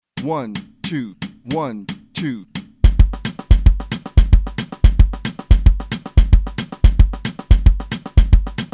Besetzung: Instrumentalnoten für Schlagzeug/Percussion